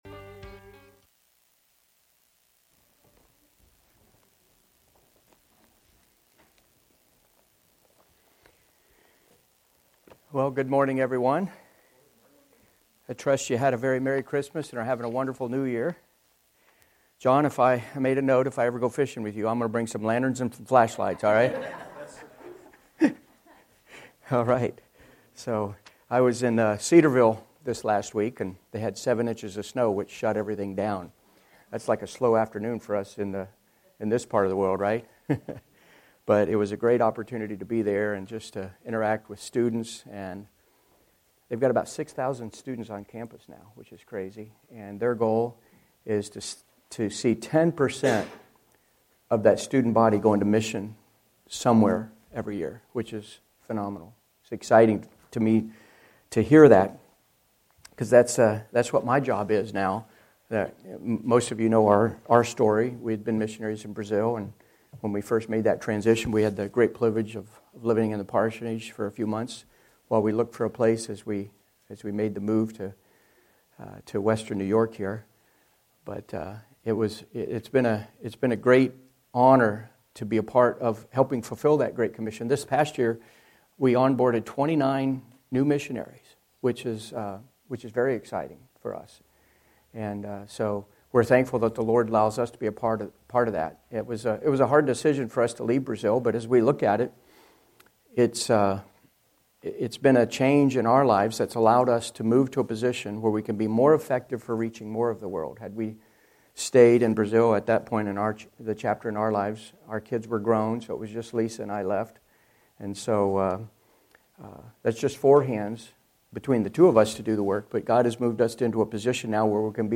Sermons by First Baptist Church of Elba